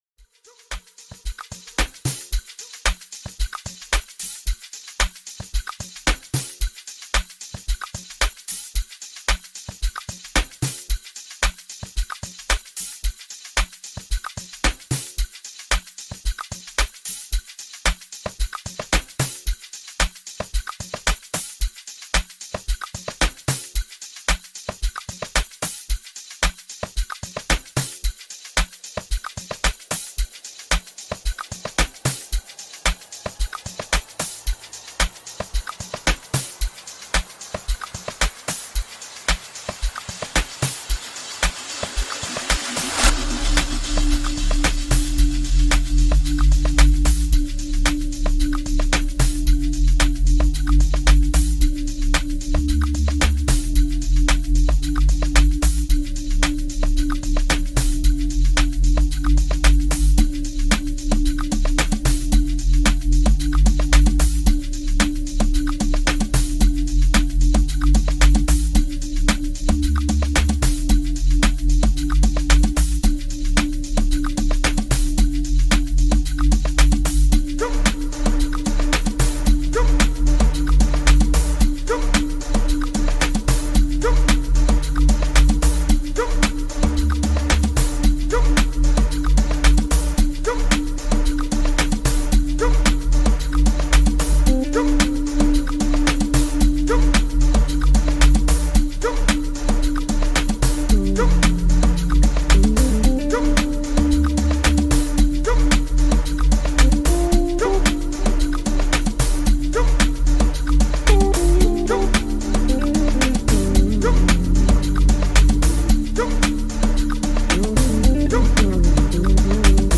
Amapiano anthem